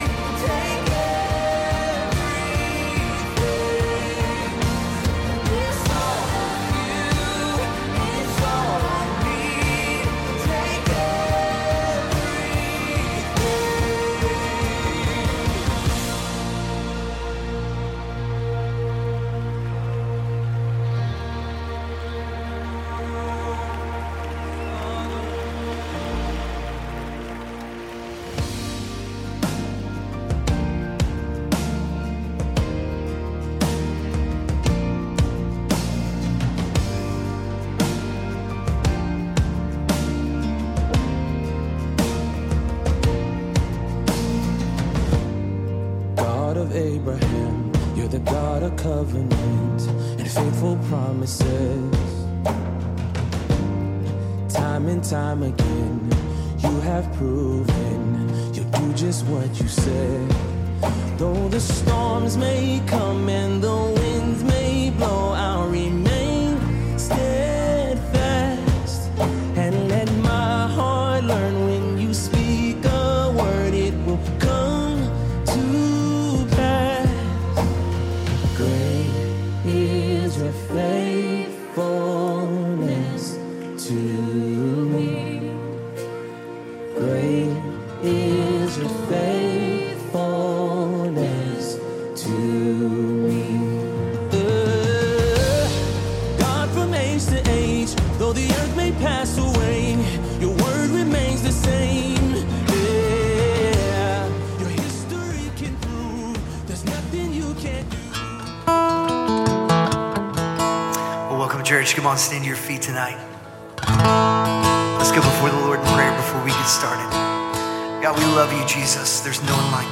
Calvary Knoxville Midweek Live!